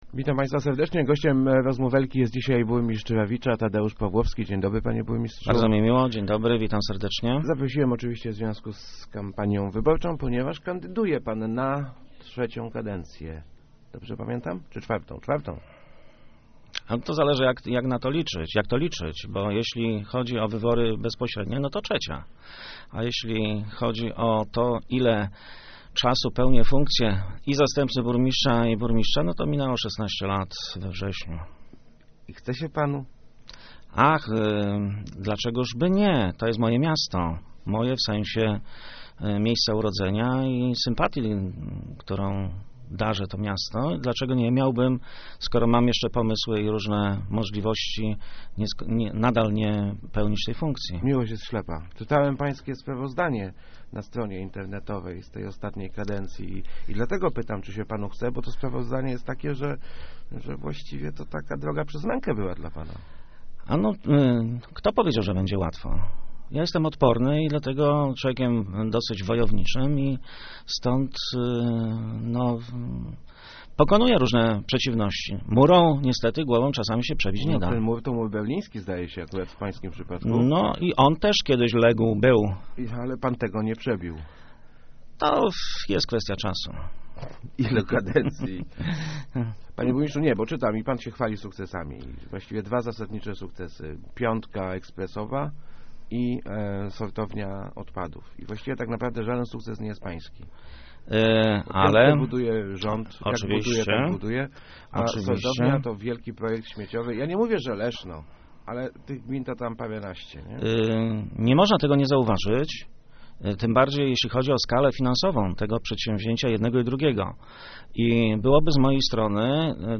Niezbudowanie wschodniej obwodnicy Rawicza to wynik stara� opozycji – mówi� w Rozmowach Elki burmistrz Tadeusz Paw�owski. Jego zdaniem jeden z radnych, staraj�cych si� o mandat burmistrza lobbowa� w Poznaniu, �eby projekt samorz�du odrzuci�.